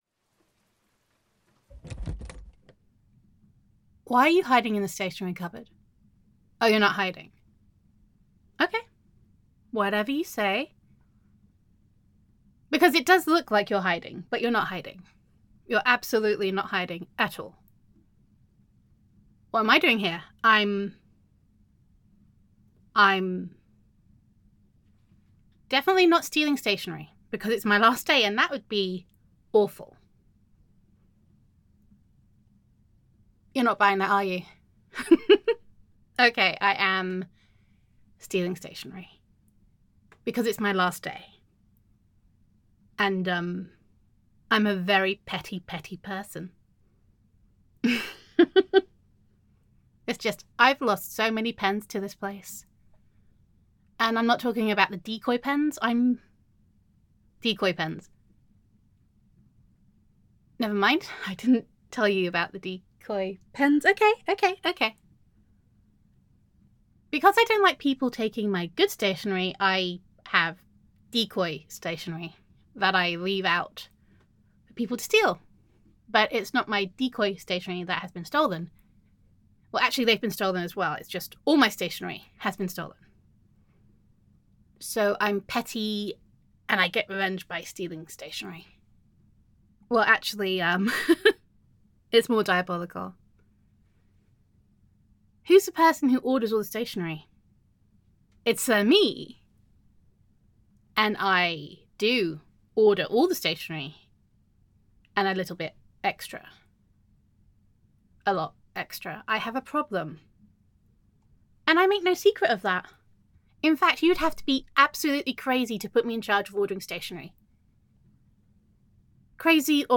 [F4A] You Are the Highlighter of My Life